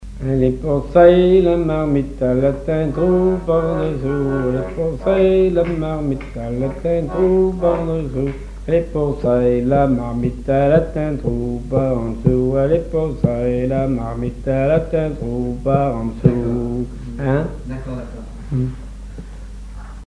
Couplets à danser
branle : courante, maraîchine
accordéon diatonique
Pièce musicale inédite